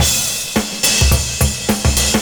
106CYMB02.wav